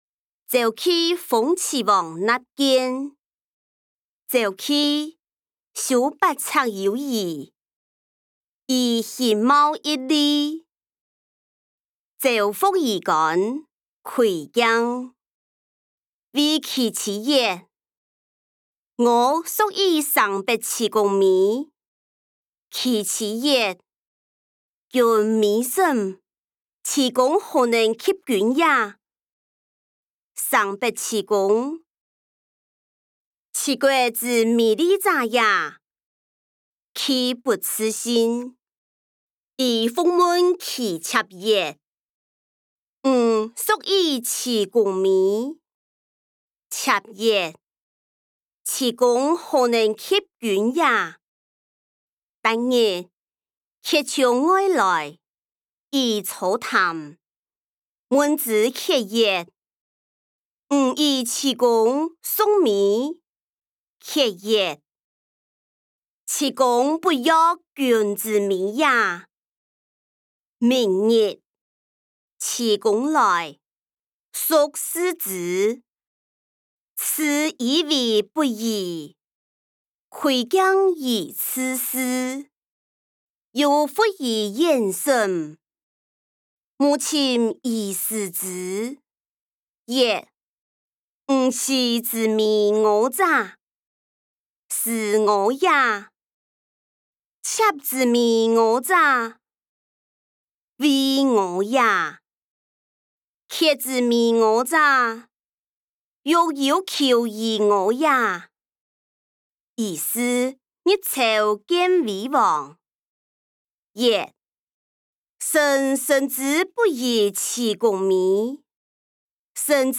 歷代散文-鄒忌諷齊王納諫音檔(四縣腔)